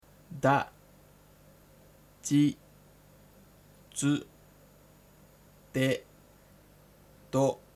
da ji dsu de do